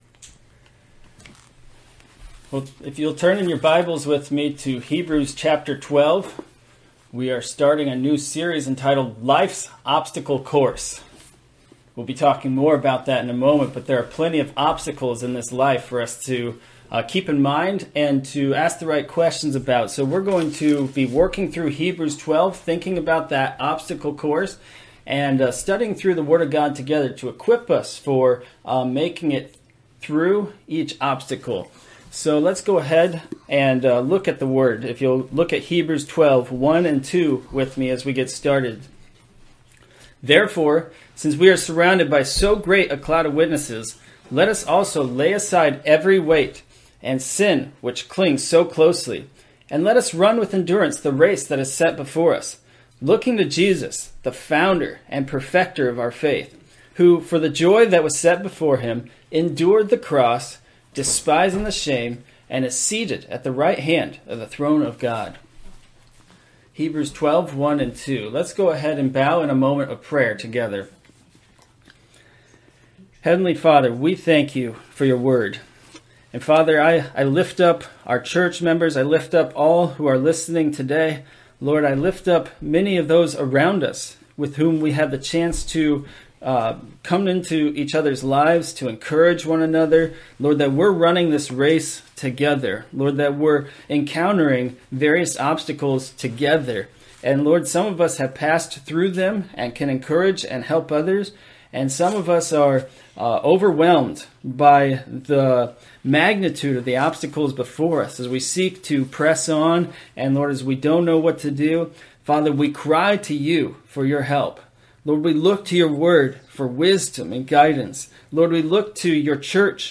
Online Sermons